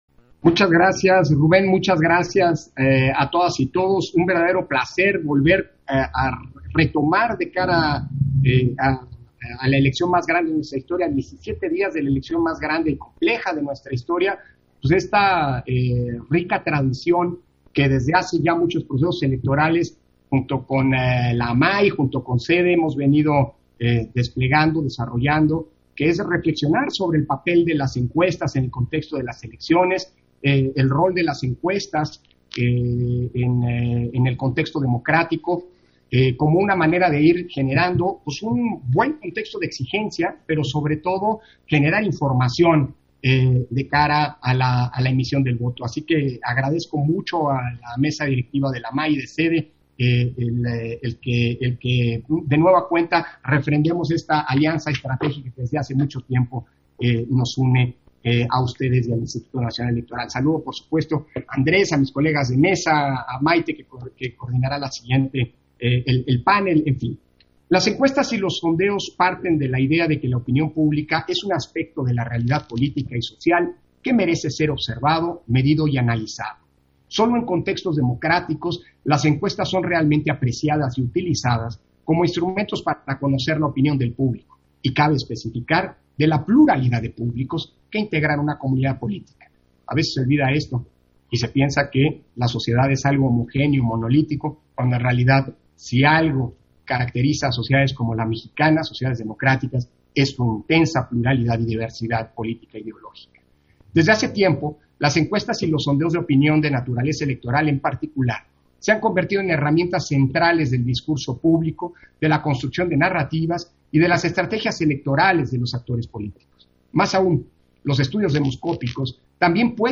Intervención de Lorenzo Córdova, en el Foro: Encuestas y elecciones rumbo al 6 de junio